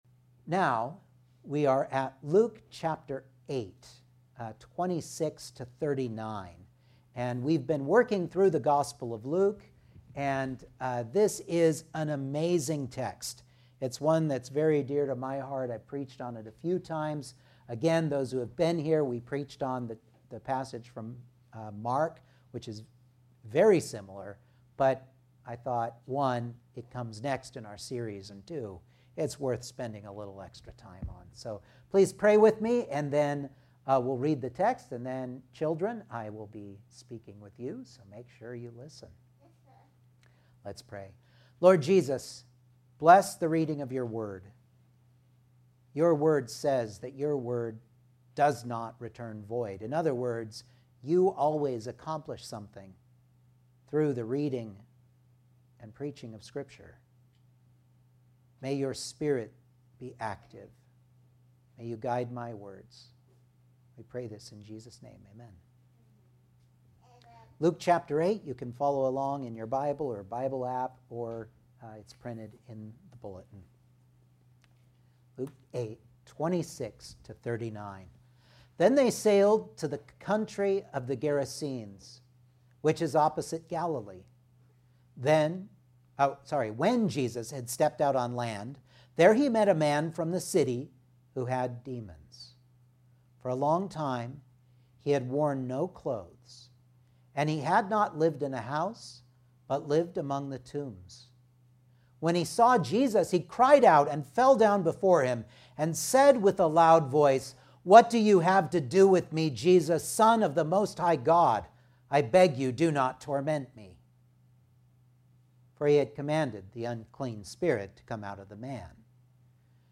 Luke 8:26-39 Service Type: Sunday Morning Outline